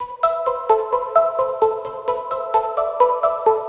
bells.wav